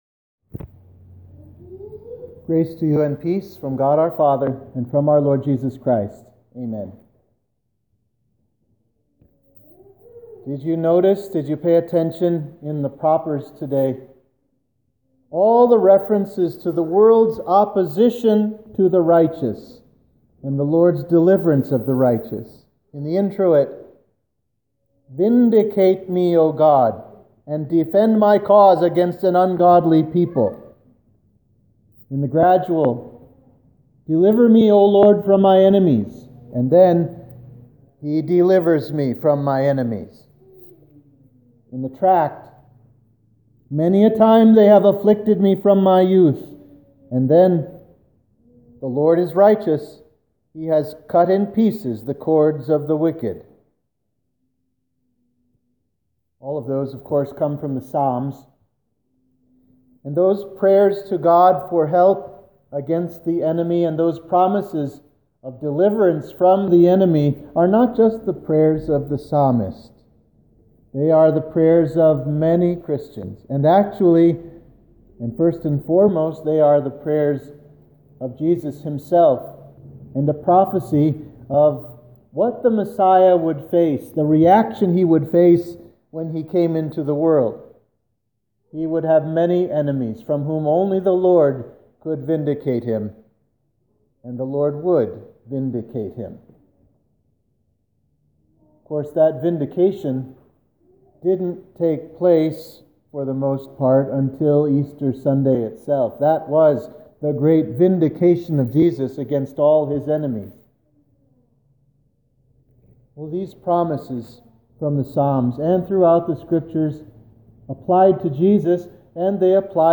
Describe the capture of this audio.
(Only sermon audio available today.)